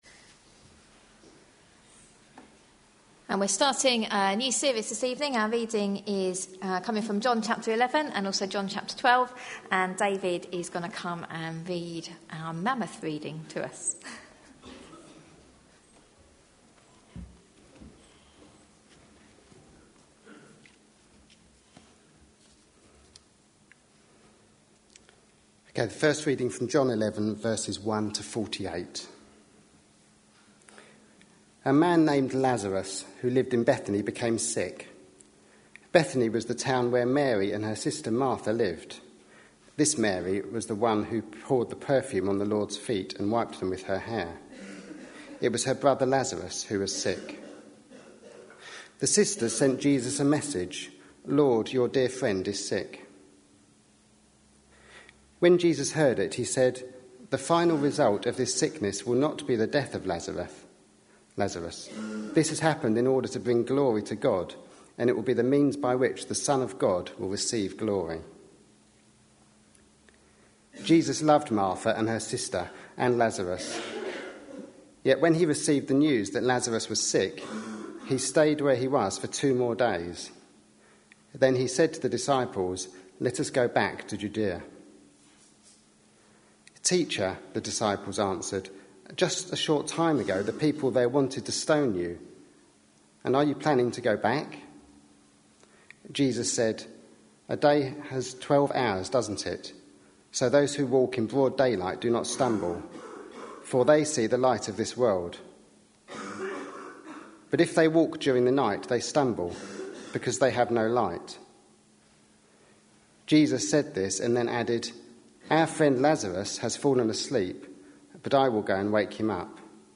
A sermon preached on 10th February, 2013, as part of our Passion Profiles and Places -- Lent 2013. series.